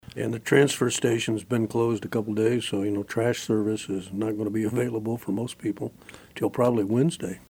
Mayor Wynn Butler says also at play this week has been a delay of trash service to most residents.